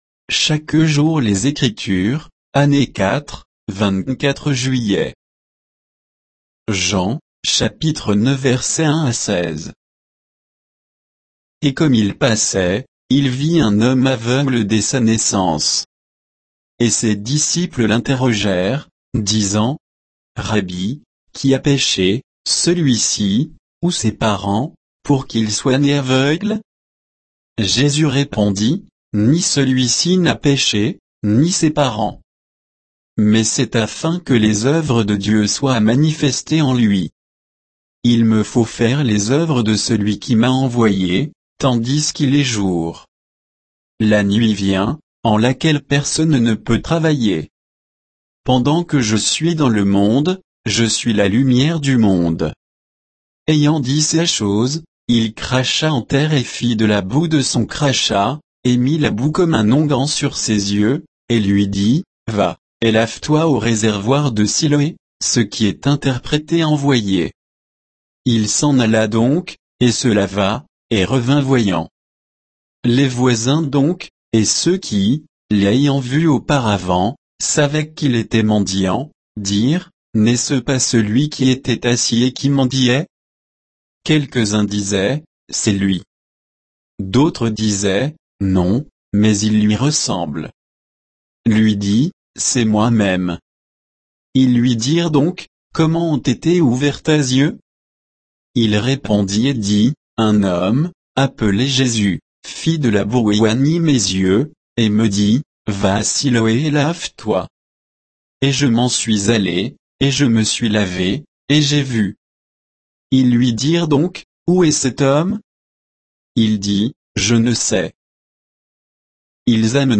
Méditation quoditienne de Chaque jour les Écritures sur Jean 9, 1 à 16